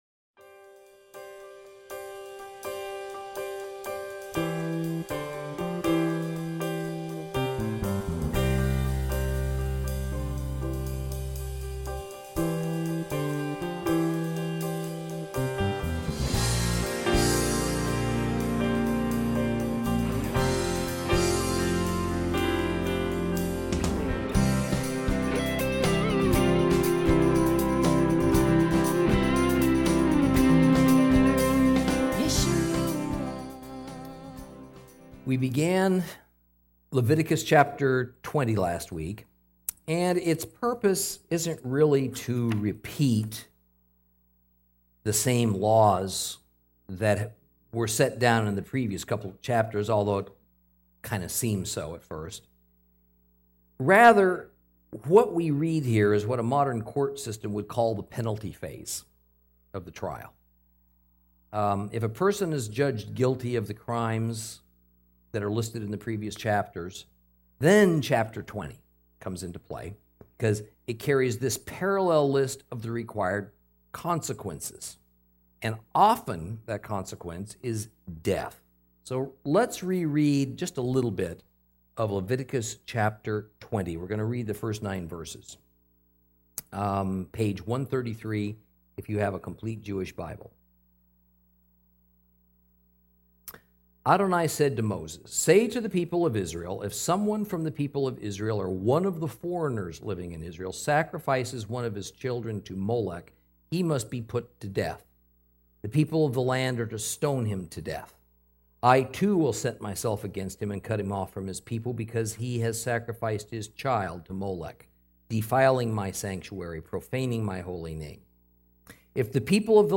Lesson 30 – Leviticus 20 & 21